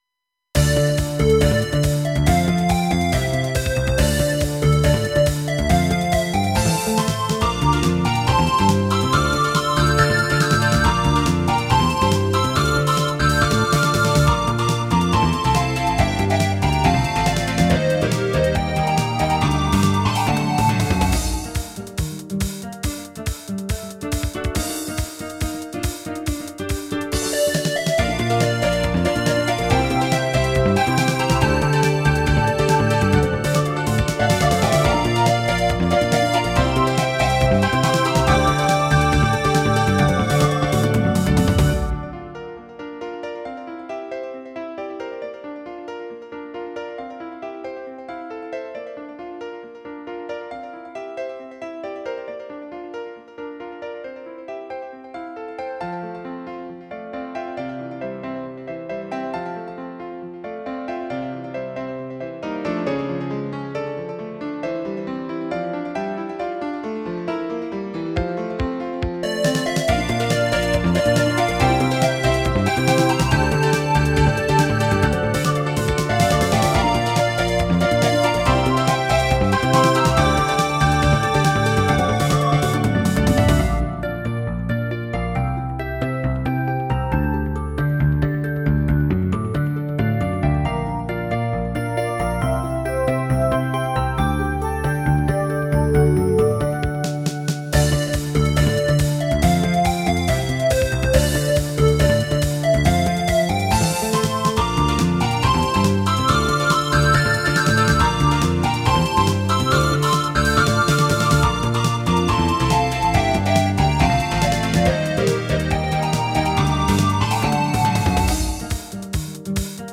私のオリジナル曲のうち、ゲームミュージック風の曲を公開いたします。
冒険感があります。